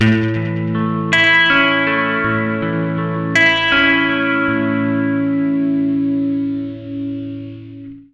80MAJARP A-R.wav